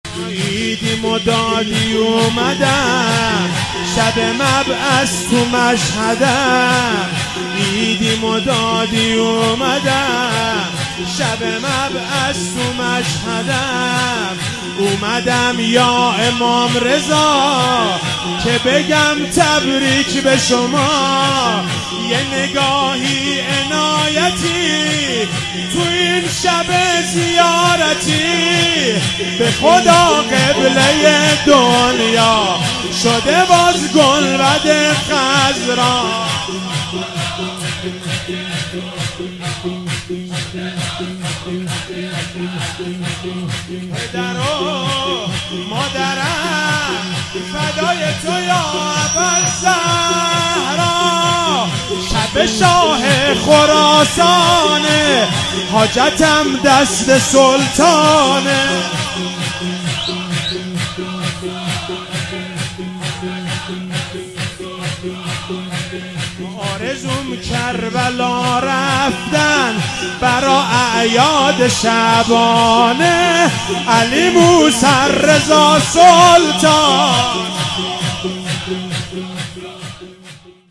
مذهبی